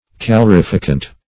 calorifacient - definition of calorifacient - synonyms, pronunciation, spelling from Free Dictionary
Calorifacient \Ca*lor`i*fa"cient\, a.
calorifacient.mp3